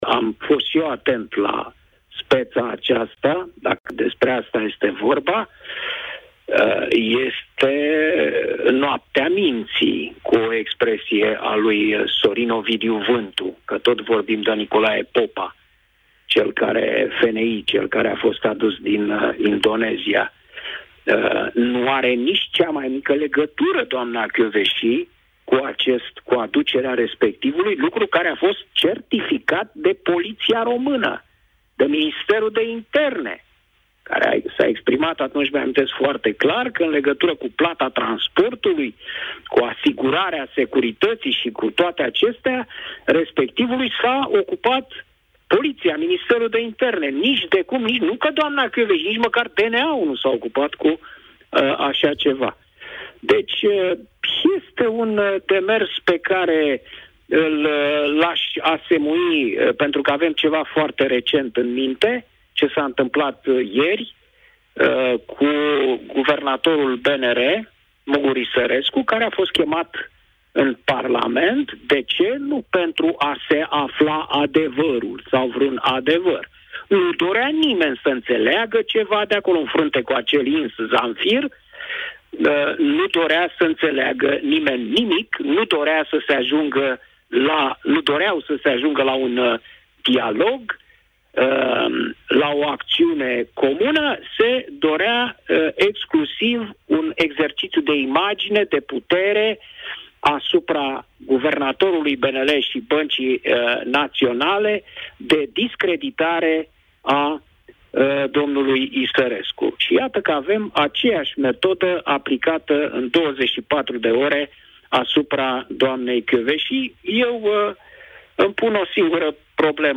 Editorialistul Cristian Tudor Popescu a comentat în direct, la Europa FM, anunţul fostei şefe a DNA privind citarea acesteia, vineri, la Secţia specială pentru investigarea infracţiunilor din Justiţiei, un demers care apare în ajunul audierii Laurei Codruţa Kovesi, în legislativul comunitar, pentru postul de procuror-şef european.